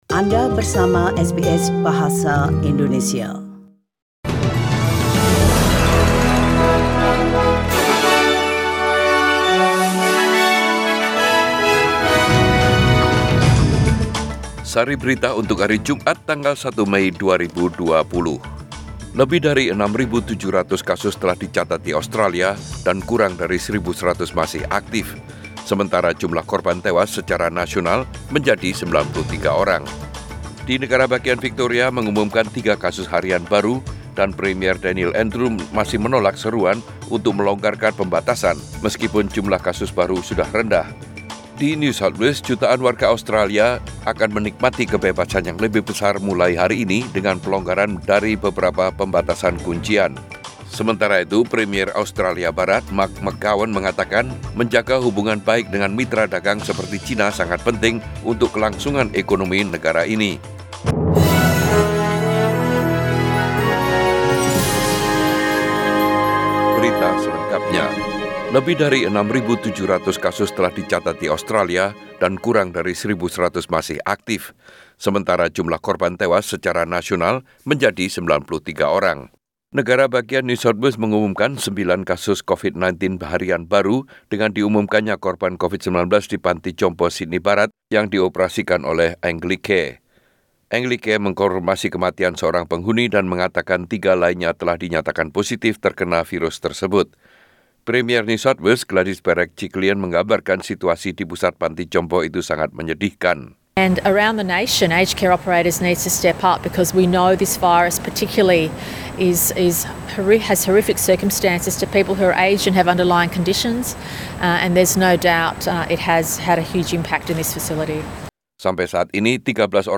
SBS Radio News in Bahasa Indonesia - 1 May 2020